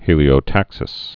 (hēlē-ō-tăksĭs)